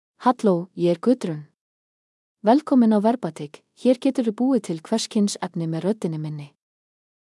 GudrunFemale Icelandic AI voice
Gudrun is a female AI voice for Icelandic (Iceland).
Voice sample
Listen to Gudrun's female Icelandic voice.
Female
Gudrun delivers clear pronunciation with authentic Iceland Icelandic intonation, making your content sound professionally produced.